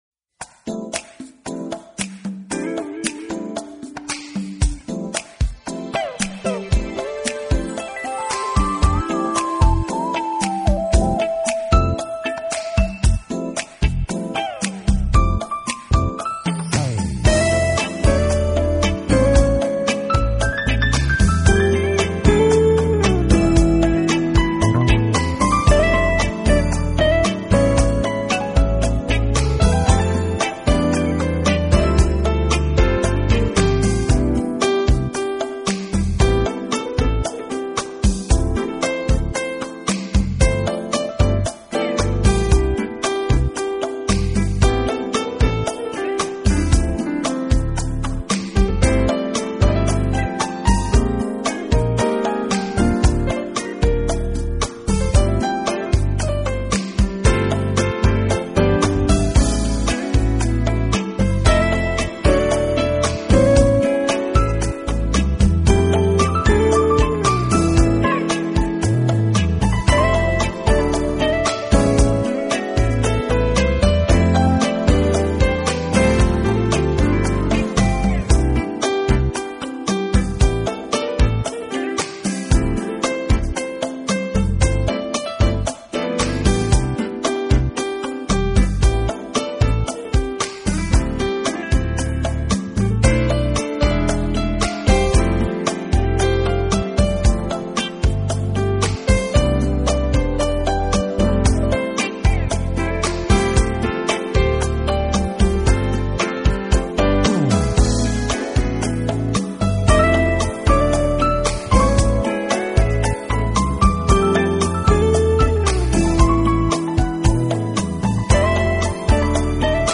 音乐类型：Jazz
acoustic & electric pianos, synthesizer, bass, programming